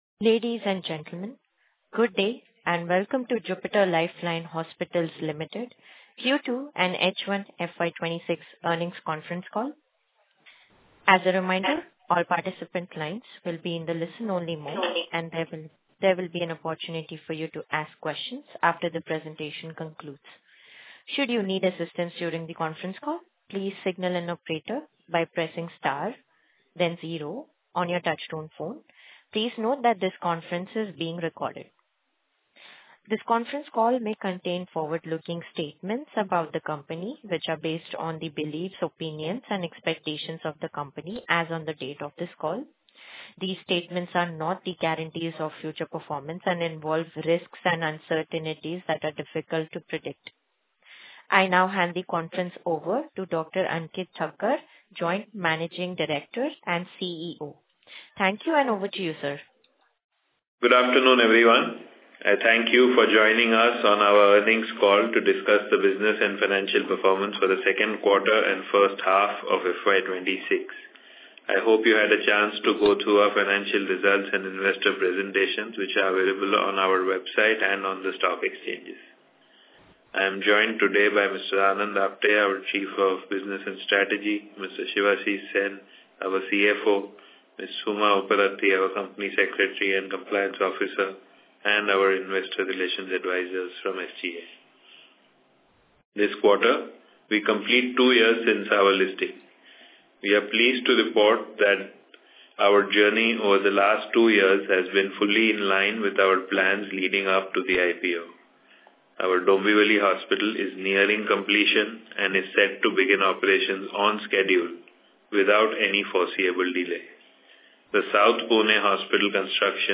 Earnings-Call-Audio-Recording–Quarter-2-H1.mp3